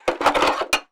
SFX_PickUp_02.wav